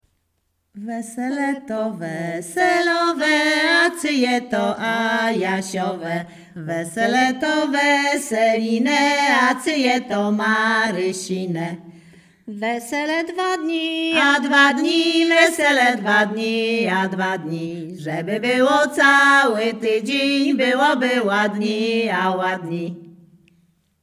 Śpiewaczki z Chojnego
Sieradzkie
Weselna
miłosne weselne wesele przyśpiewki